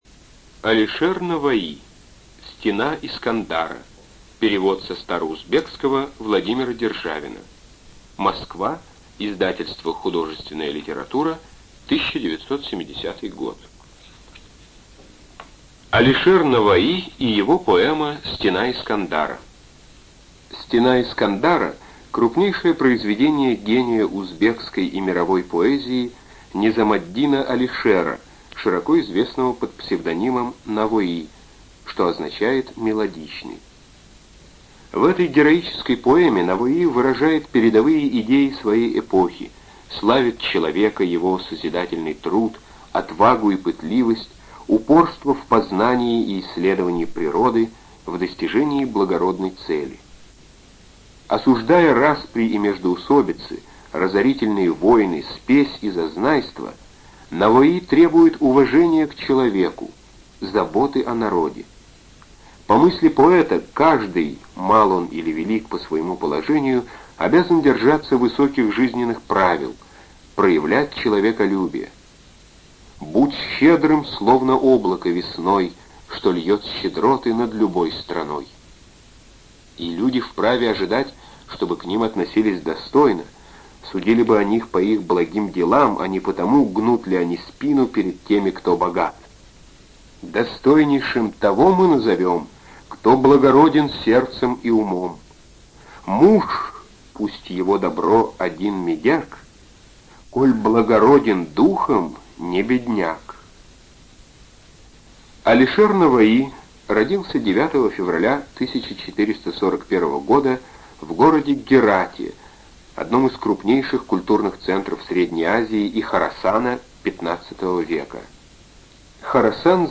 ЖанрПоэзия
Студия звукозаписиЛогосвос